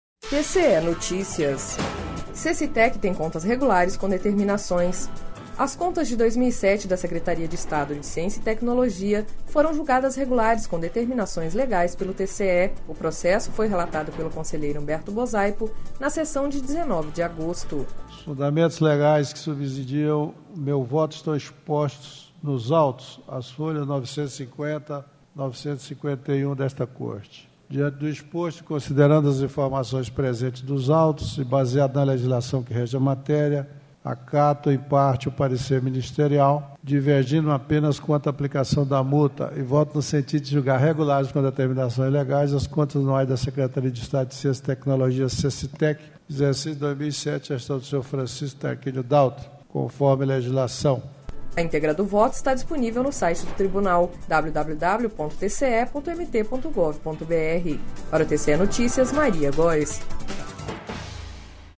Sonora: Humberto Bosaipo – conselheiro TCE-MT